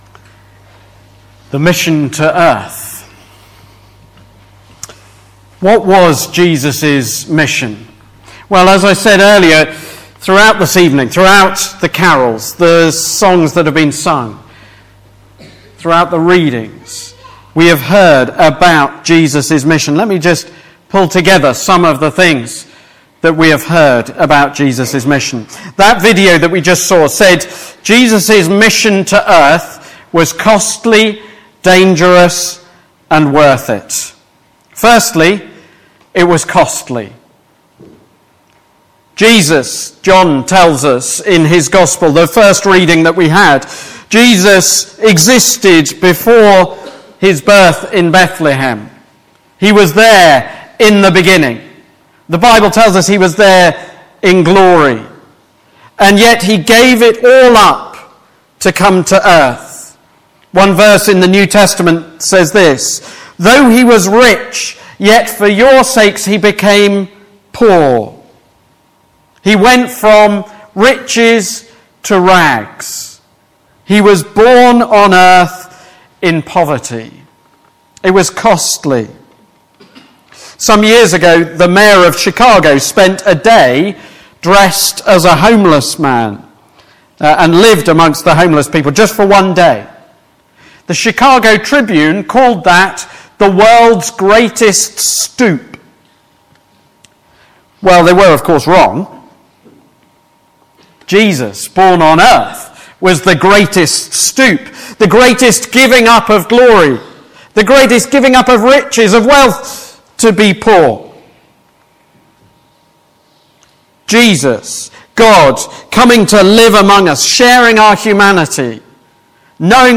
Mission to Earth (Carol Concert Talk)
Christmas Service Type: Carol Concert Topics